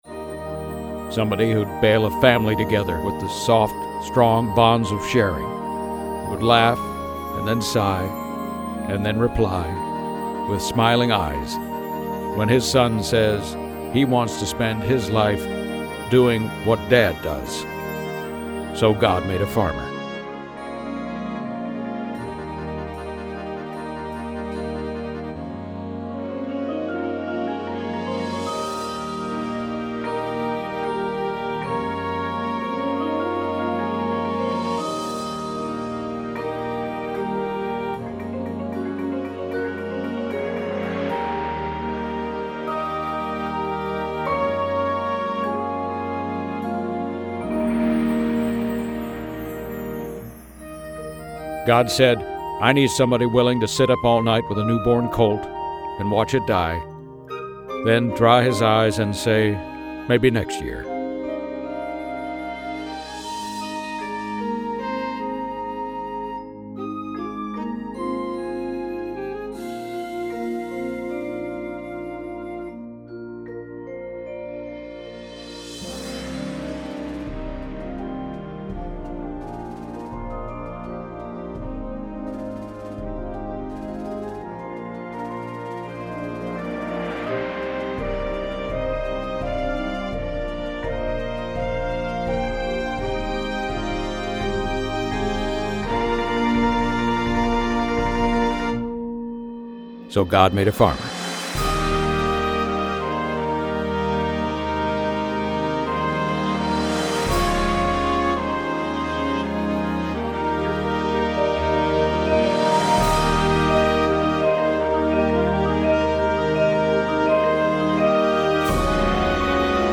• Flute
• Clarinet 1, 2
• Alto Sax
• Trumpet 1, 2
• Horn in F
• Low Brass 1, 2
• Tuba
• Snare Drum
• Synthesizer
• Marimba 1, 2
• Glockenspiel